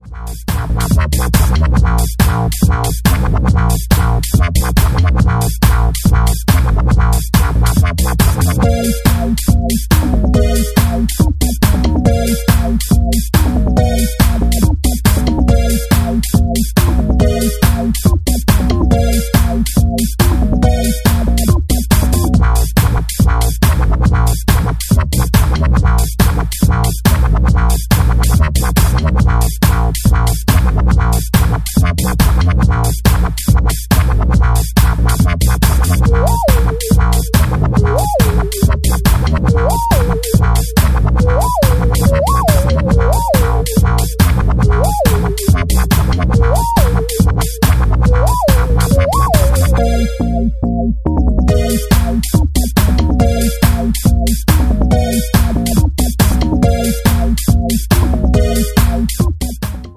140 bpm